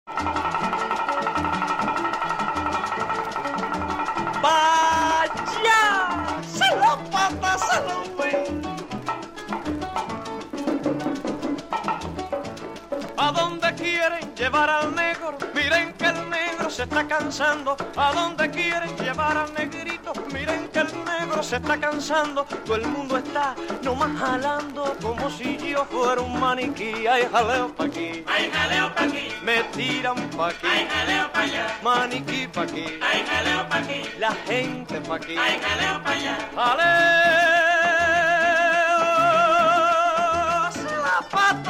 Género: Latin, Folk, World, & Country
Estilo: Guaguancó, Cubano, Bolero, Son, Guajira, Guaracha